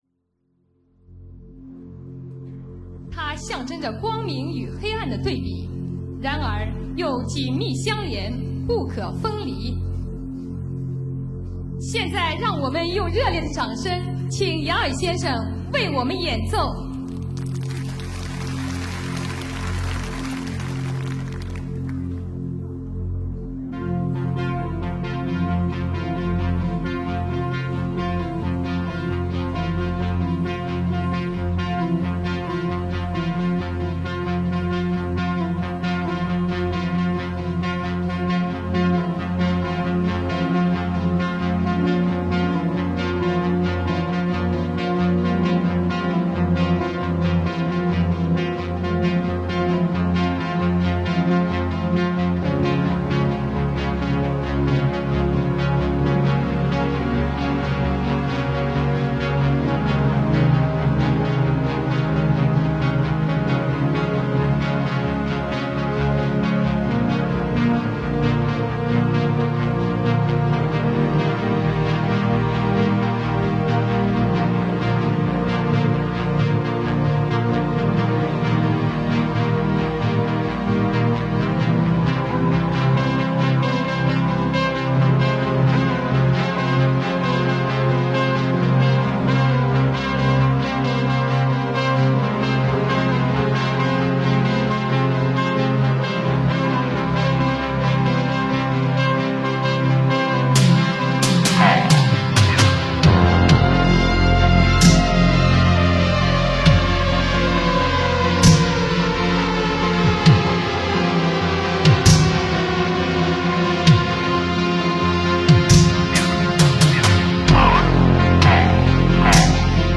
Electronic, Synthwave, New Age